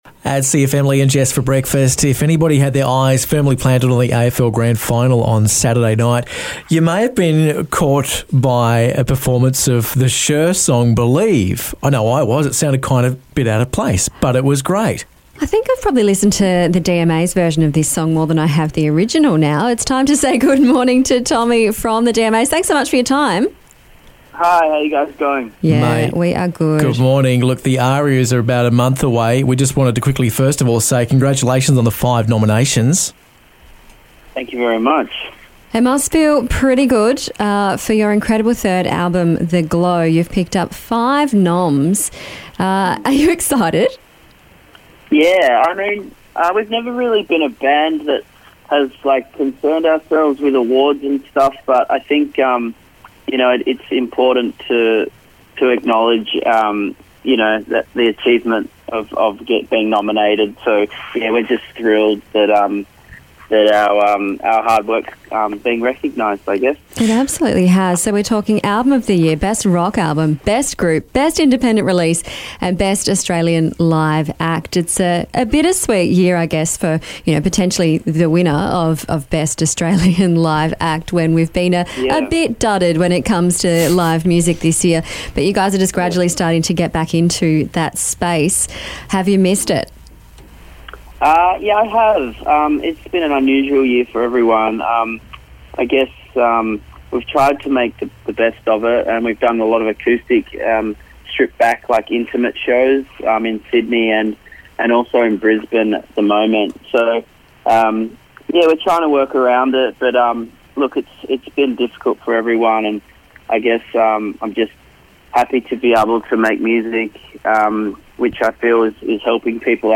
DMA's INTERVIEW